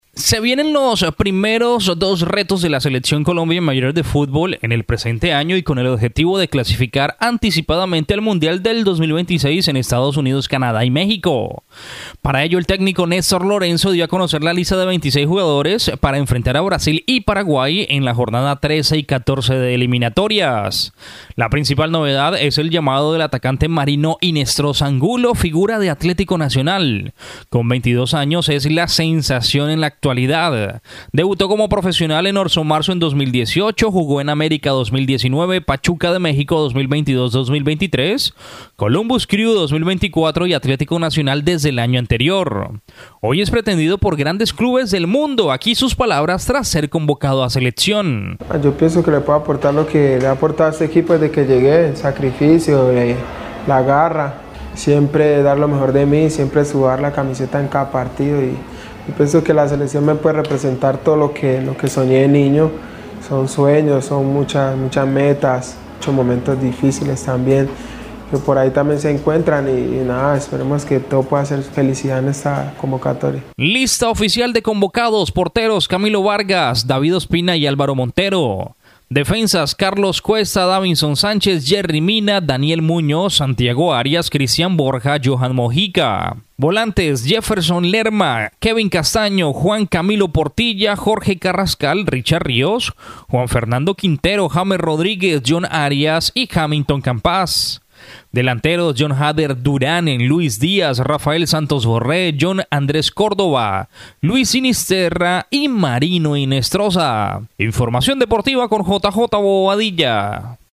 Hoy es pretendido por grandes clubes del mundo, aquí sus palabras tras ser convocado.
VOZ_TITULAR_DEPORTES_14_MARZO.MP3